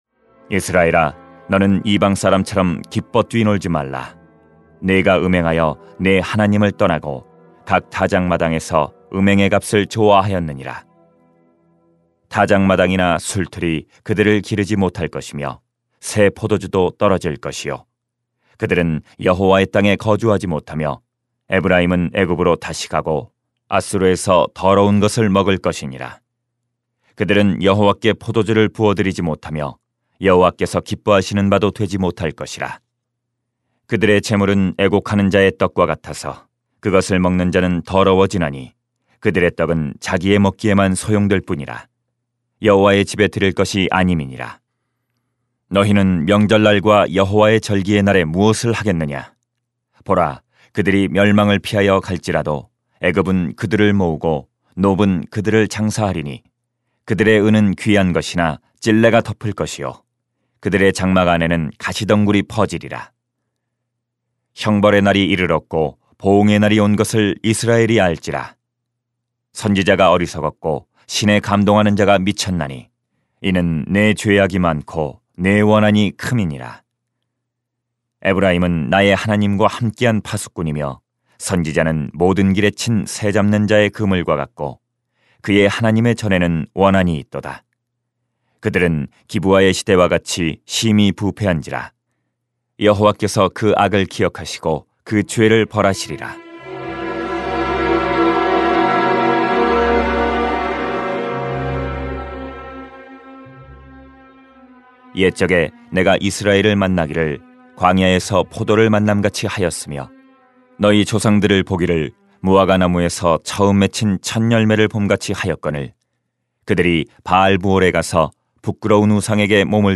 [호 9:1-17] 기뻐할 수 없는 이유 > 새벽기도회 | 전주제자교회